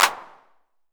TR 909 Clap.wav